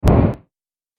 جلوه های صوتی
دانلود صدای آتش 3 از ساعد نیوز با لینک مستقیم و کیفیت بالا